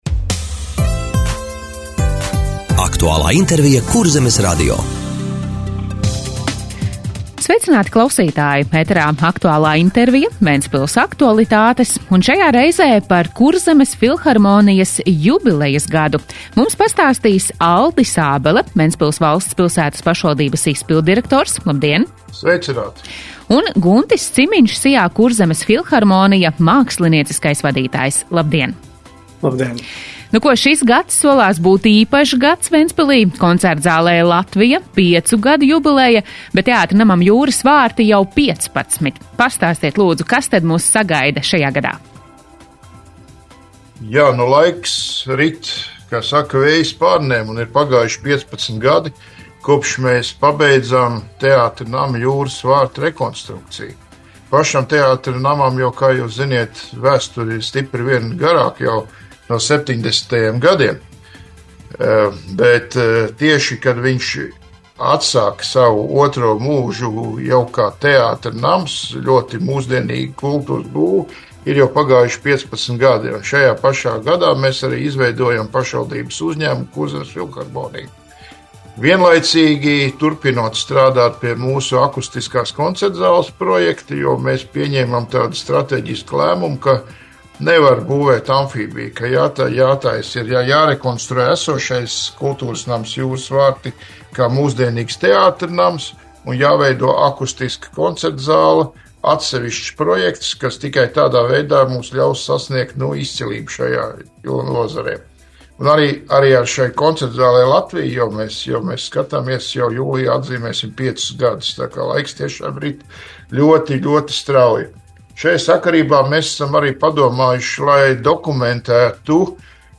Radio saruna “Kurzemes filharmonijai jubilejas gads”